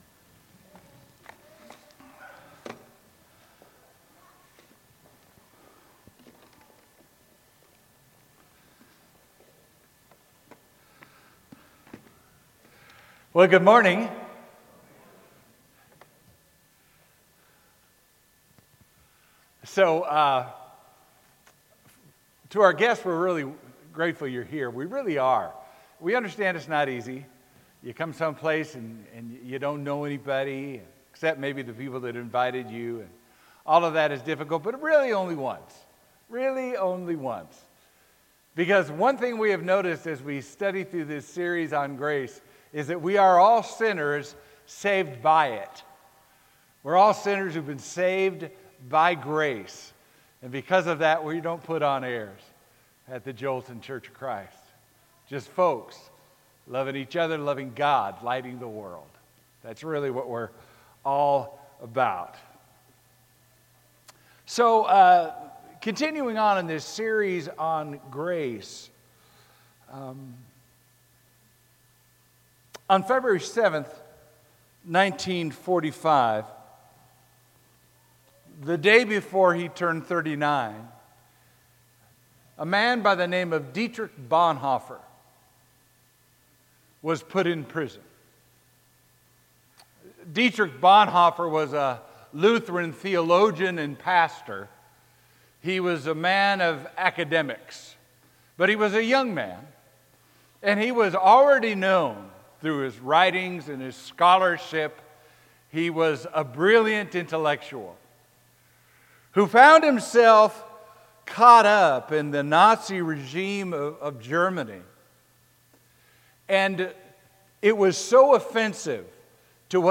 Sermon: Grace is the Difference. “Cheap Grace”